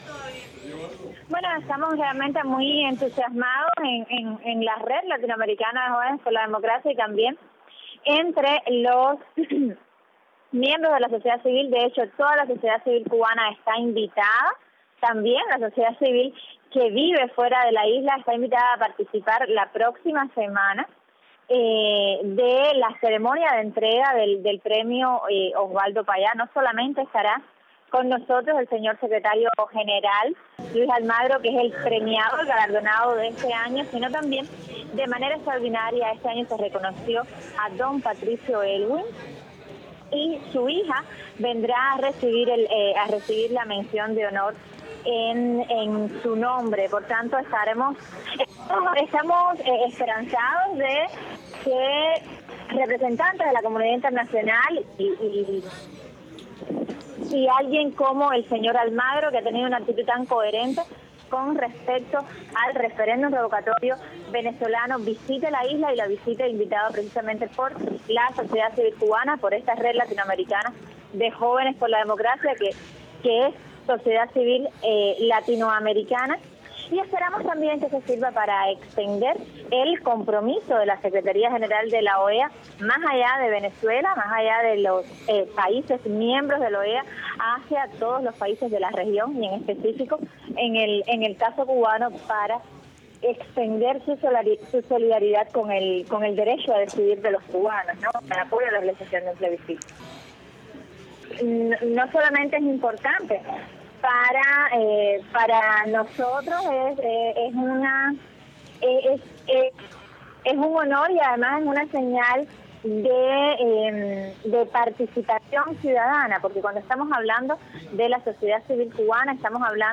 Rosa María Payá entrevistada